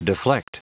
Prononciation du mot deflect en anglais (fichier audio)
Prononciation du mot : deflect